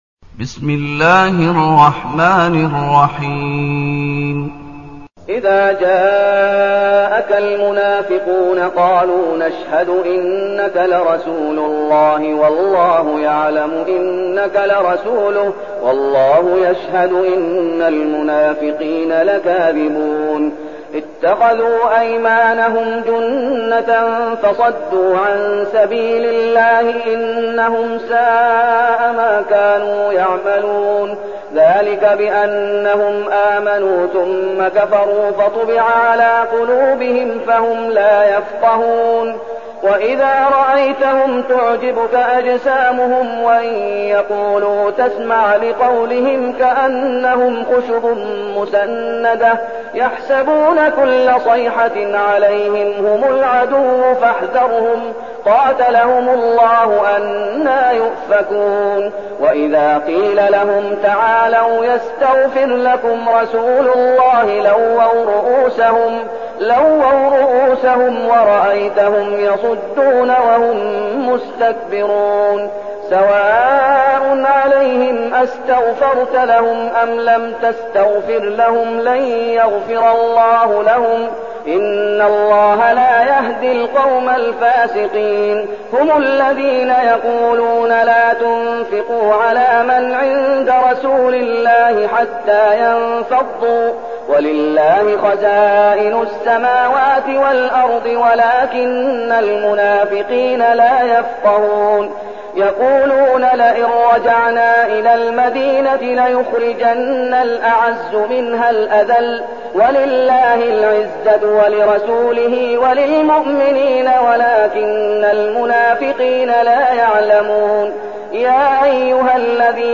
المكان: المسجد النبوي الشيخ: فضيلة الشيخ محمد أيوب فضيلة الشيخ محمد أيوب المنافقون